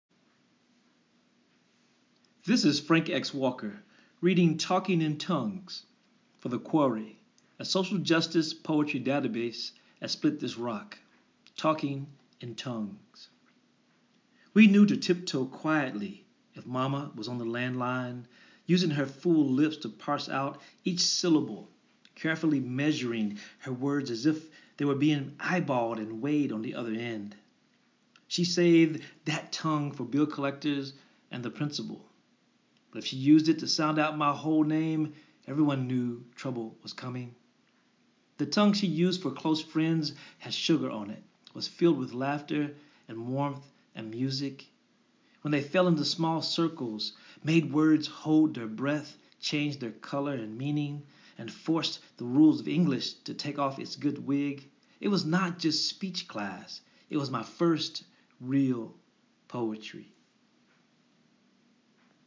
Listen as Frank X Walker reads "Talking in Tongues."